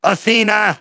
synthetic-wakewords
ovos-tts-plugin-deepponies_Demoman_en.wav